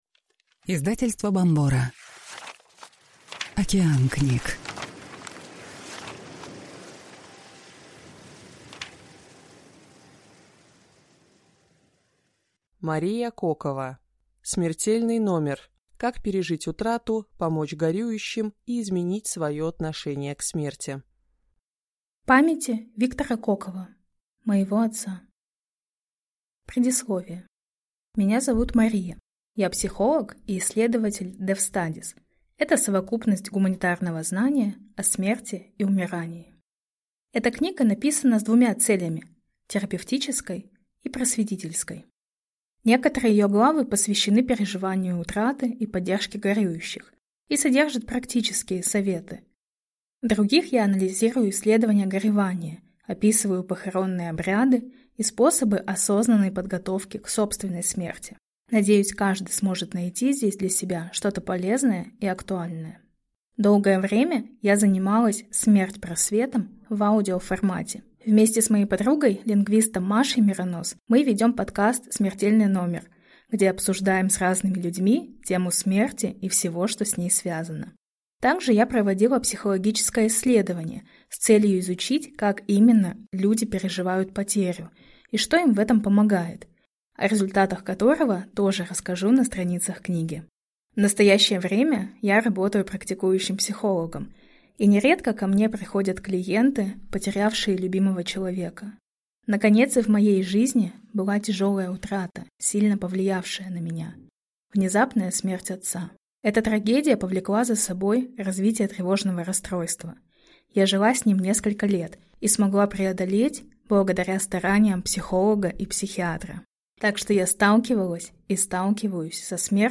Аудиокнига Смертельный номер. Как пережить утрату, помочь горюющим и изменить свое отношение к смерти | Библиотека аудиокниг